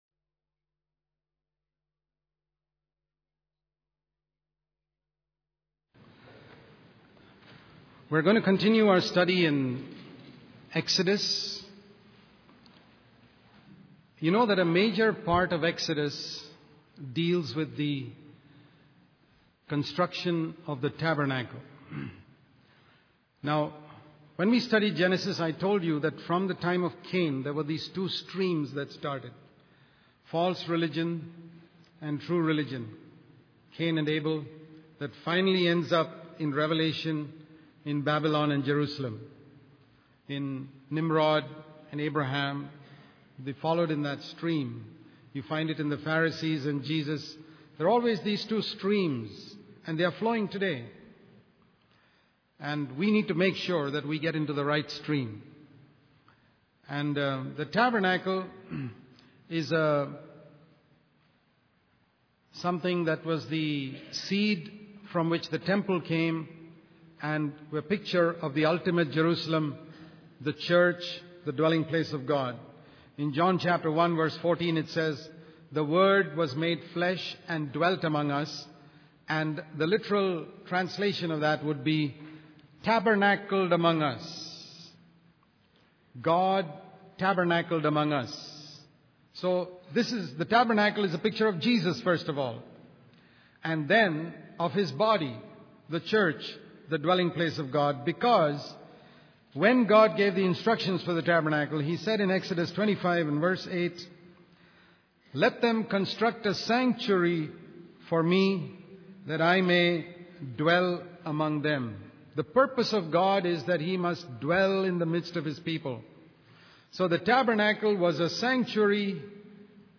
In this sermon, the speaker discusses the tabernacle described in the book of Exodus. He explains that the tabernacle is a picture of the body of Christ, with believers closely held together in fellowship.